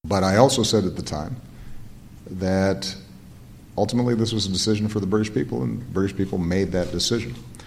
at-weak-Barack-Obama.mp3